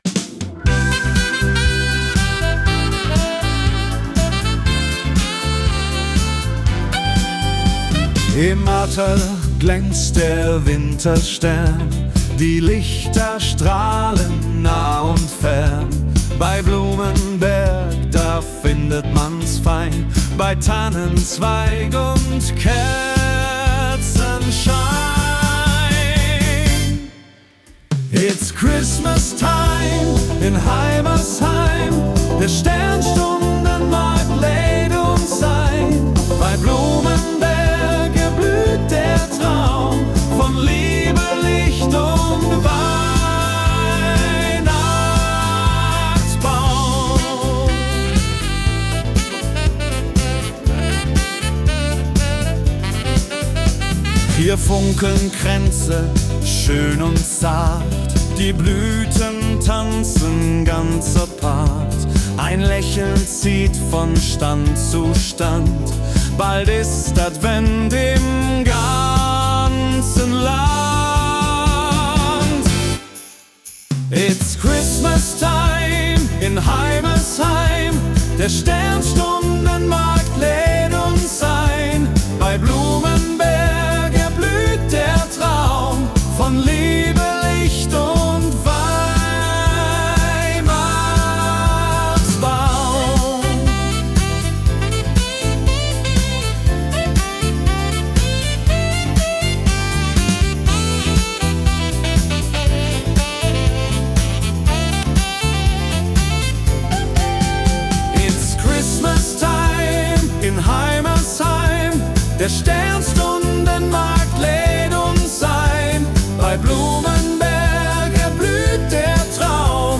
Nach Ihrem Briefing und Input erstelle ich Konzept, Text und Sounddesing für Ihren Song, die Einspielung erfolgt dann durch KI-Softwaretools.